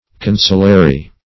consulary - definition of consulary - synonyms, pronunciation, spelling from Free Dictionary
Consulary \Con"su*la"ry\